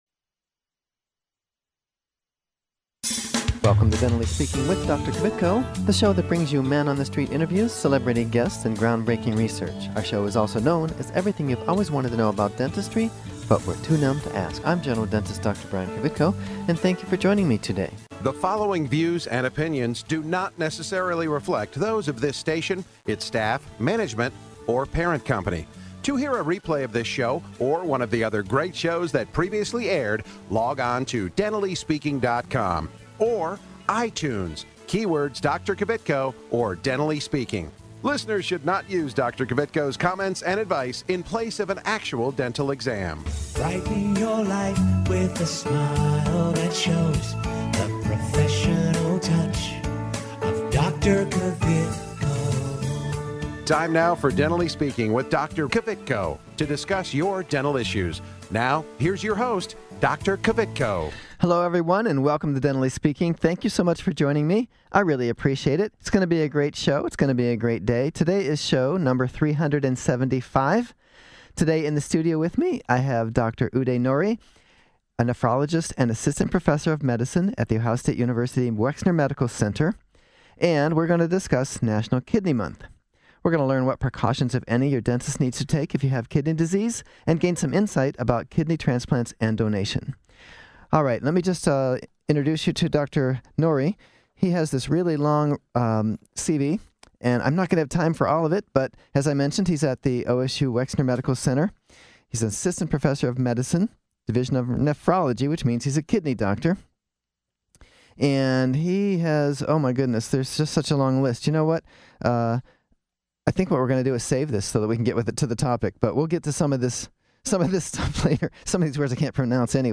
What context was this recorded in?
live in the studio to discuss National Kidney Month. Learn what precautions if any your dentist needs to take if you have kidney disease gain insight about kidney transplants and donation.